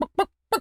chicken_cluck_bwak_seq_12.wav